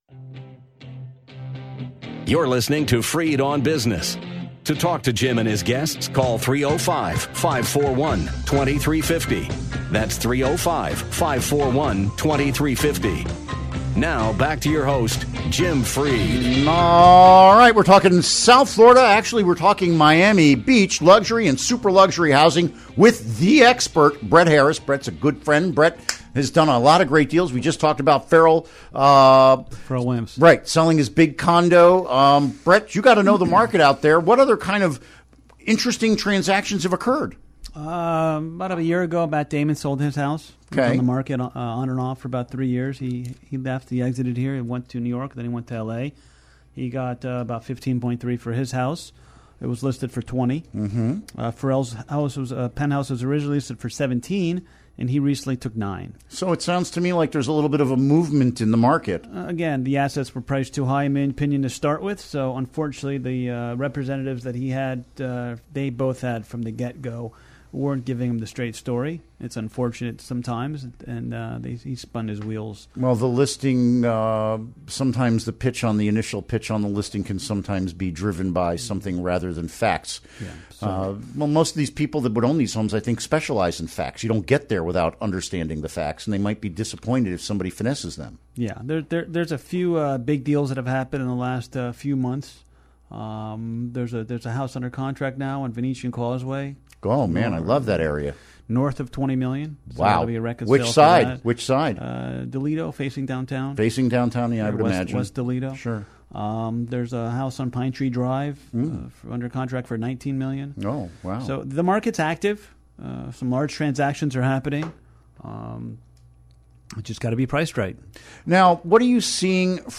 We discuss the state of the Super Lux housing market in Greater Miami, with a focus on Miami Beach. Interview Segment Episode 375: 06-30-16 Click here to download Part 1 (To download, right-click and select “Save Link As”.)